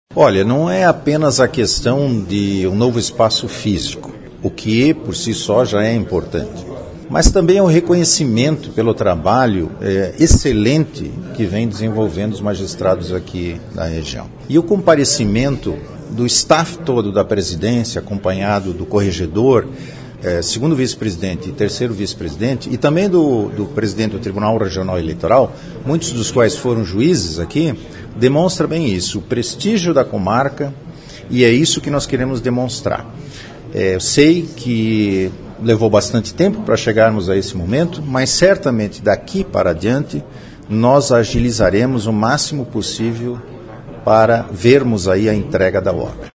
Em sua primeira visita institucional, o presidente do Tribunal de Justiça de Santa Catarina, desembargador Rubens Schulz, destacou que a obra traduz o compromisso da atual gestão em oferecer melhores condições de atendimento à população.